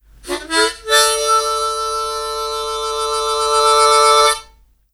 Sonido de armónica 2
aerófono
viento
armónica
lengüeta